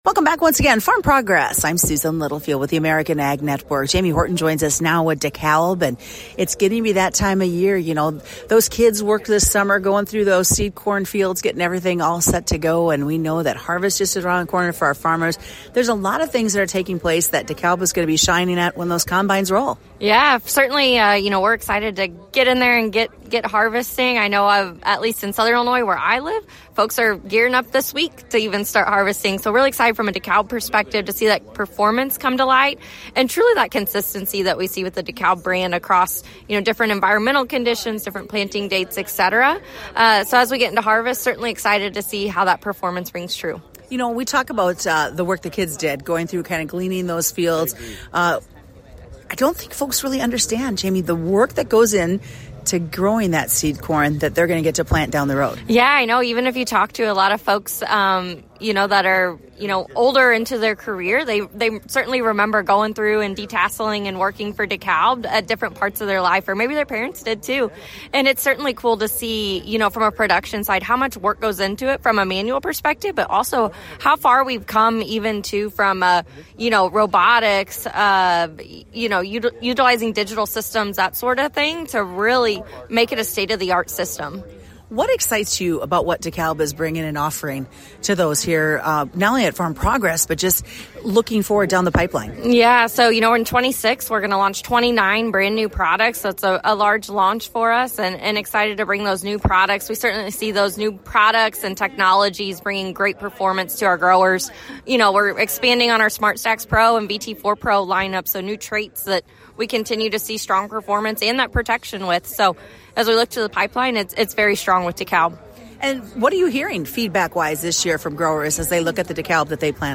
During the 2025 Farm Progress Show, we learned about the latest from Dekalb and what is in the pipeline moving forward.